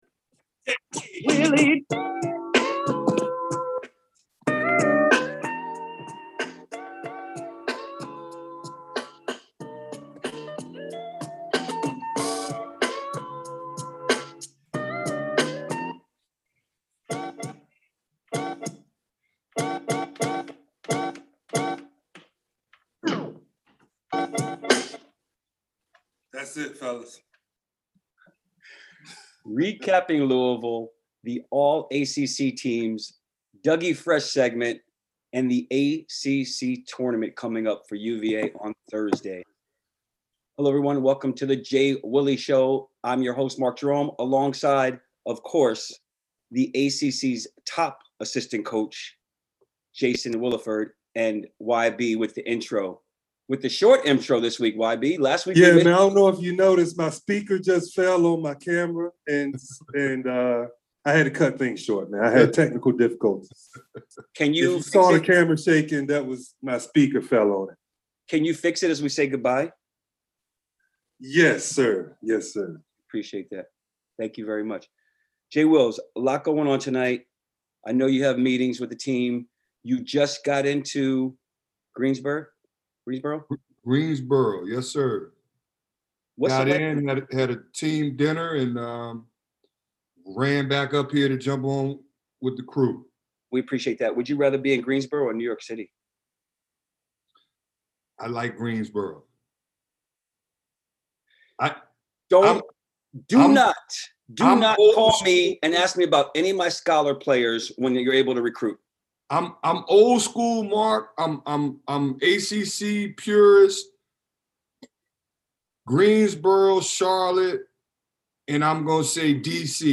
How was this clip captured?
Live on location at the ACC Tournament.